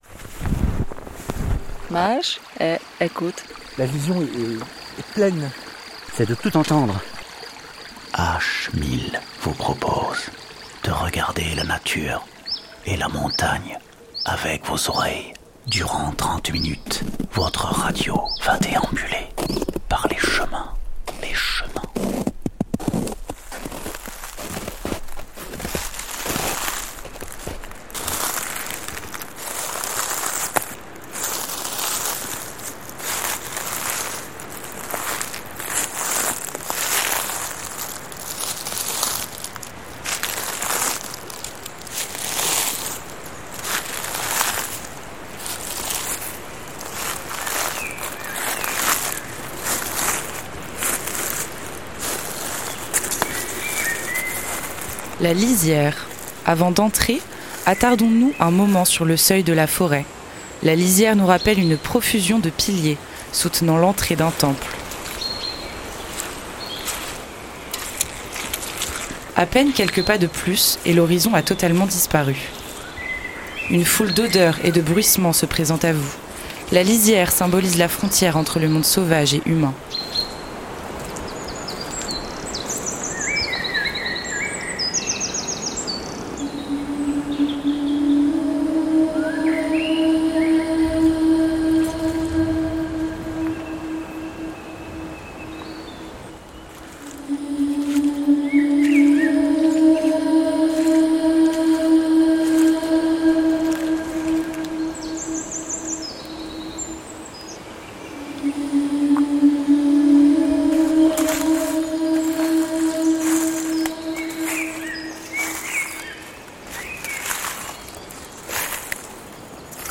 Cette semaine dans H1000, découvrez une carte postale auditive autour de la forêt !
H1000 propose, micro en main, une randonnée acoustique à travers les chaînes montagneuses. Quelque soit le site ou la saison, H1000 fait traverser à l’auditeur un paysage sonore où se mêlent les sons des grands espaces montagnards et les impressions des personnages qui le traversent. Crampons, piolets, interviews de randonneurs rencontrés au hasard du chemin, faune, flore… Tout le vécu de l’aventure d’une randonnée en montagne est restitué dans ses moindres détails.